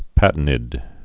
(pătn-ĭd, pə-tēnĭd)